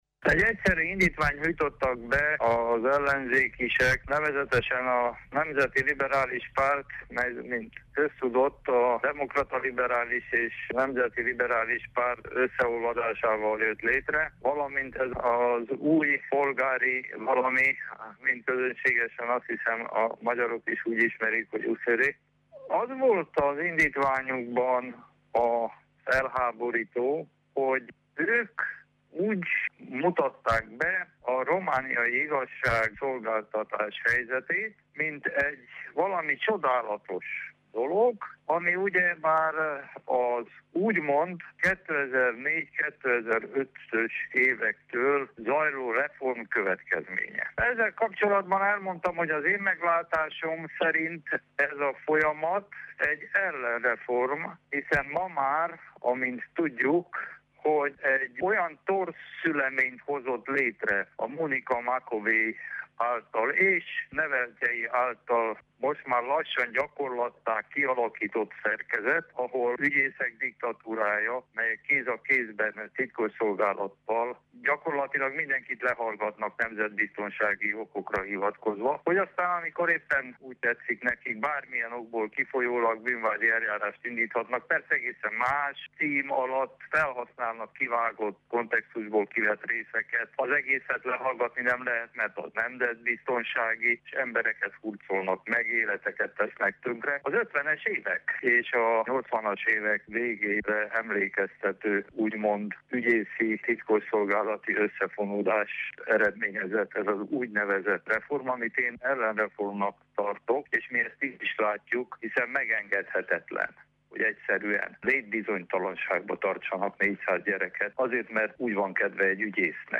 A hazai igazságügynek az uniós elveket kéne követnie, véli Márton Árpád az RMDSZ háromszéki politikusa, aki az igazságügy reformját szorgalmazza, és egyelőre ellenreformként értékeli mindazt, ami eddig történt az igazságügyben. A politikus a keddi képviselőházi tárgyaláson szólalt fel, ahol az ellenzéki pártok nyújtottak be előzetesen indítványt.